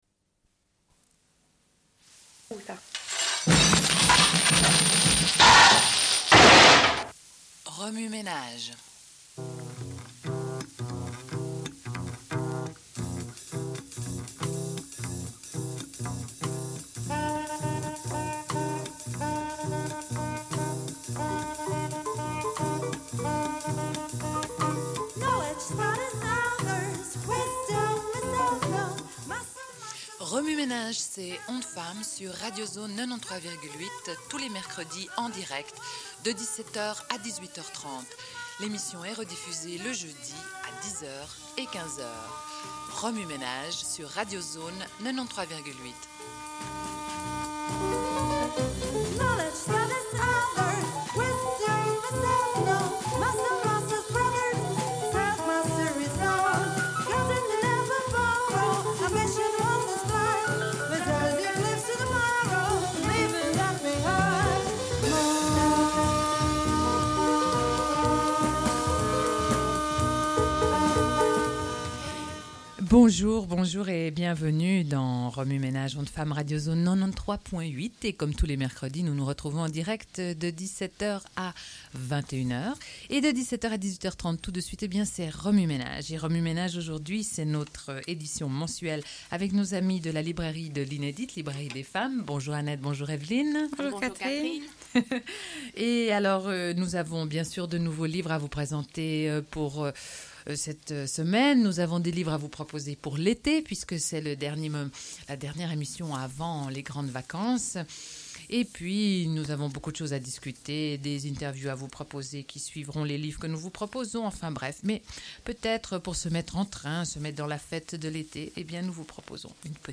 Genre access points Radio